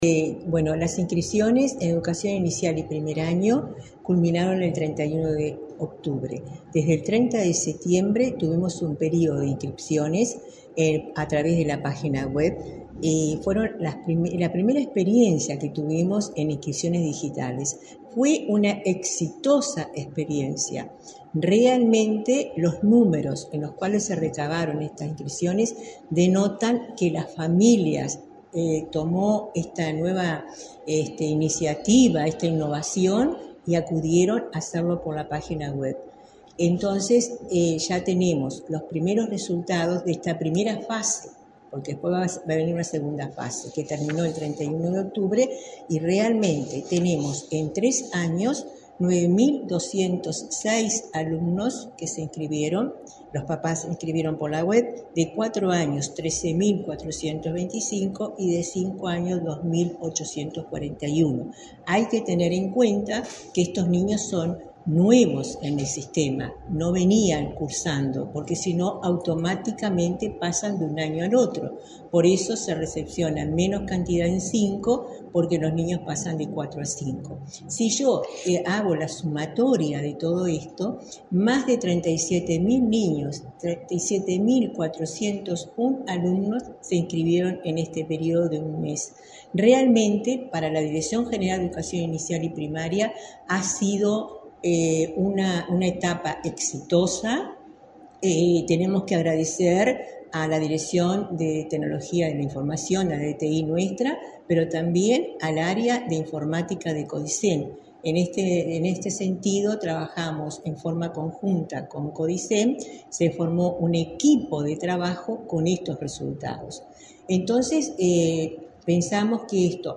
Entrevista a la directora general de Educación Inicial y Primaria, Olga de las Heras